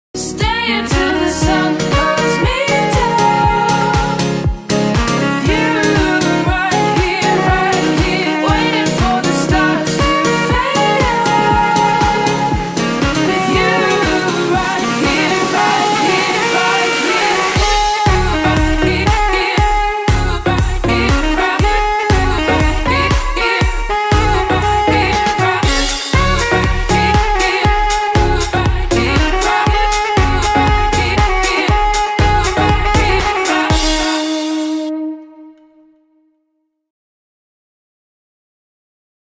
5 of which have been partying within the house music scene.
He requires his own DJ with tracks to play to.